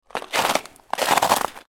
Ice Crunch Sound Effect
Cracking, breaking ice from walking on it. Human footsteps on frozen snow. Winter sounds.
Ice-crunch-sound-effect.mp3